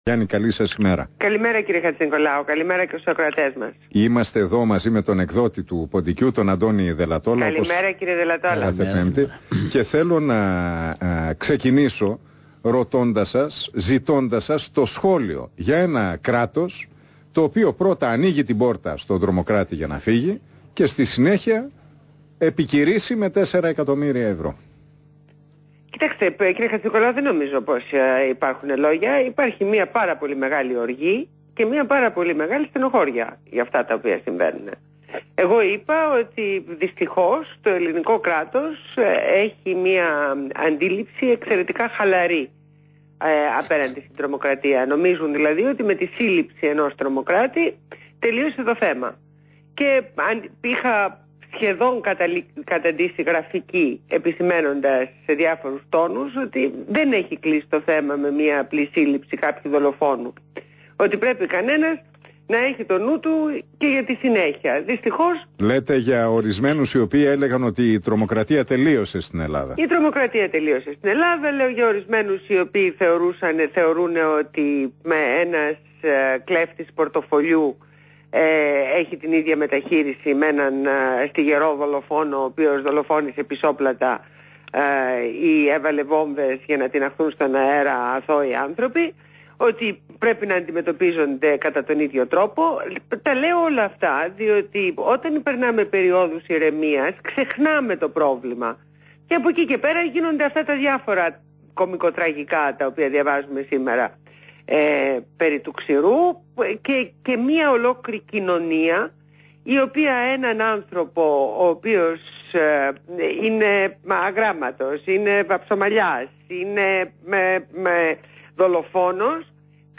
Συνέντευξη στο ραδιόφωνο REAL fm